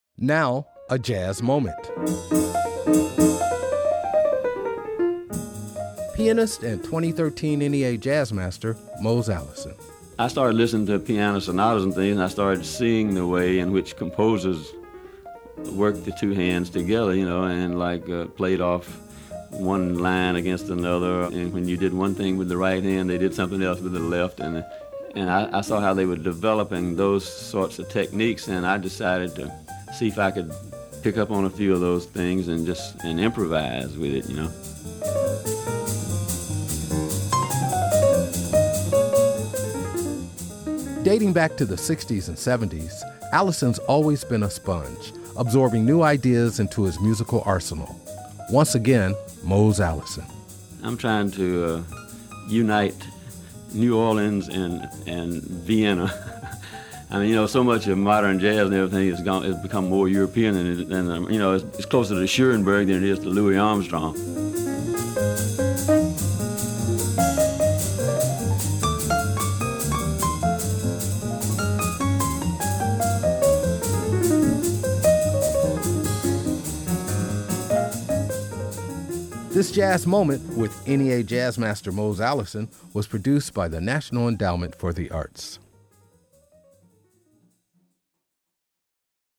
Excerpt of "Scamper” written and performed by Mose Allison from his cd, Back Country Suite, used courtesy of Concord Music, Inc. and used by permission of Audre Mae Music Inc. (BMI).